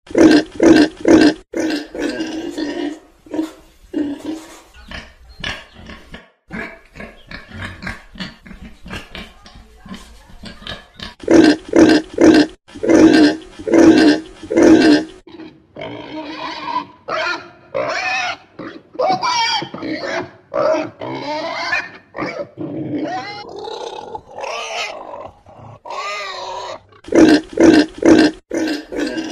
Dzwonki Świnia
Kategorie Zwierzęta